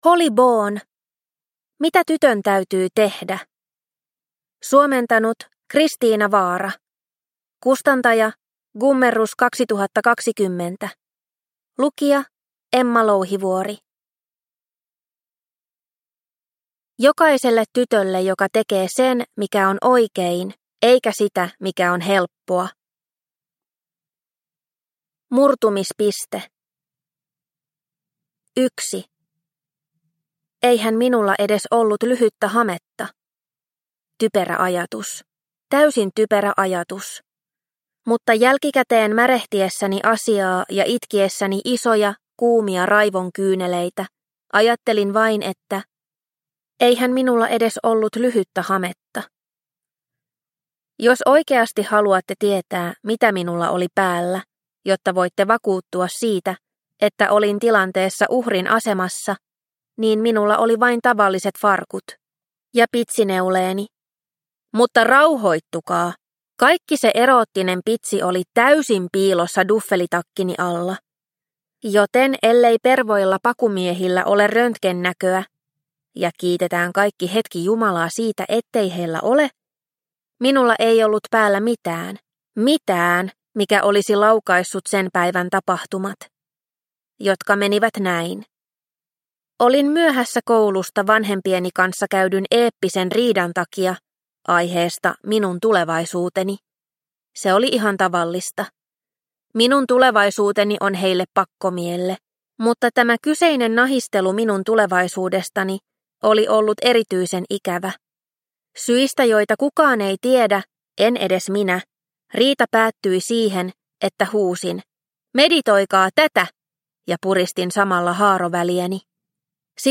Mitä tytön täytyy tehdä? – Ljudbok – Laddas ner